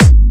VEC3 Clubby Kicks
VEC3 Bassdrums Clubby 064.wav